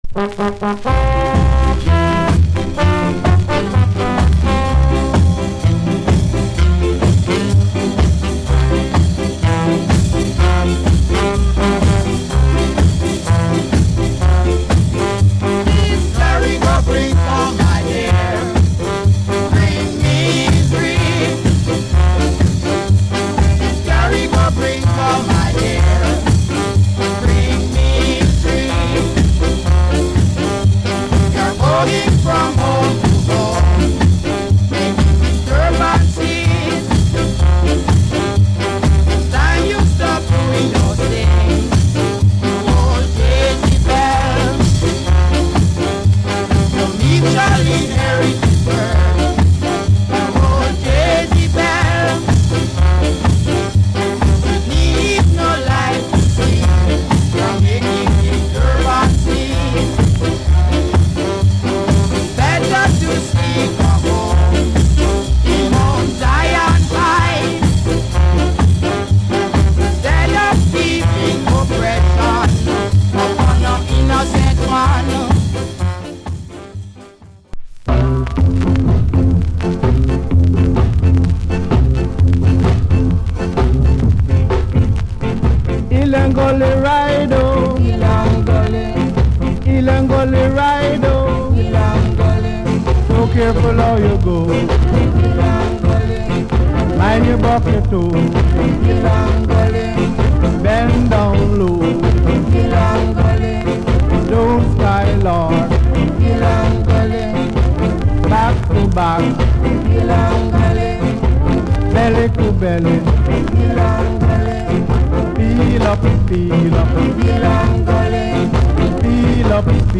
Genre Ska / Group Vocal